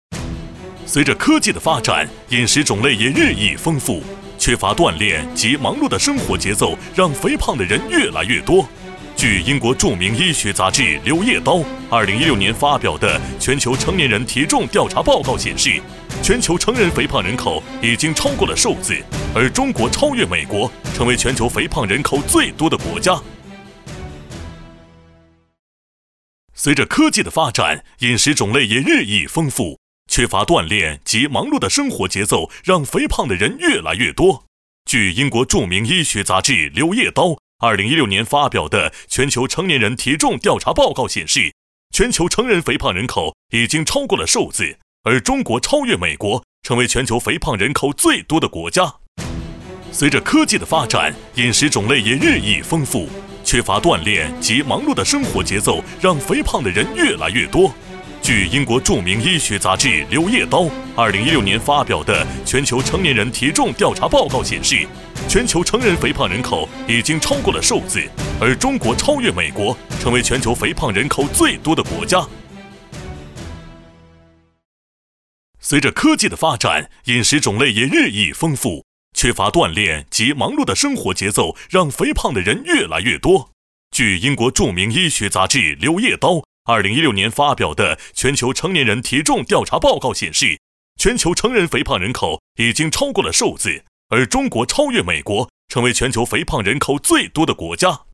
• 男S310 国语 男声 促销广告 【激情促销】可立纤CSM减脂餐 大气浑厚磁性|积极向上